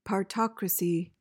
PRONUNCIATION:
(par-TOK-ruh-see)